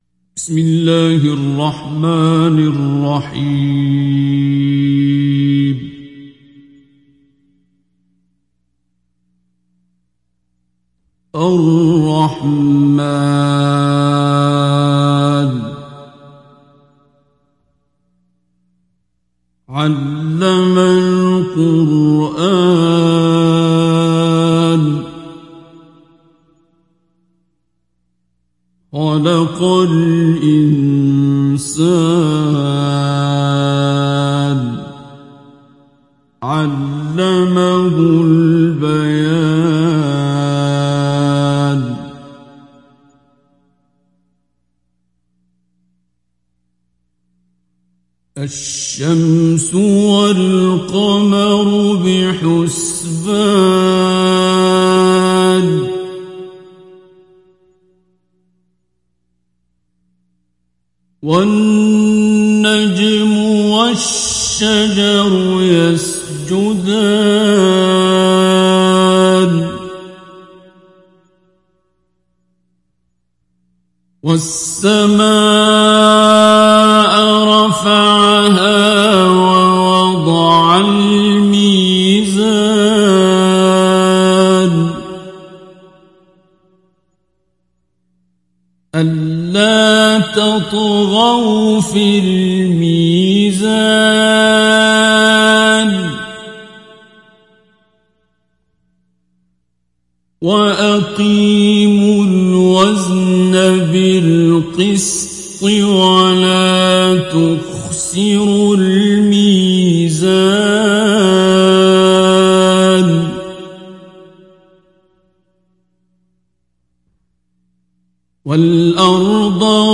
Télécharger Sourate Ar Rahman Abdul Basit Abd Alsamad Mujawwad